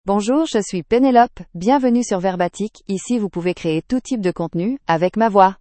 FemaleFrench (Canada)
Voice sample
Female
Penelope delivers clear pronunciation with authentic Canada French intonation, making your content sound professionally produced.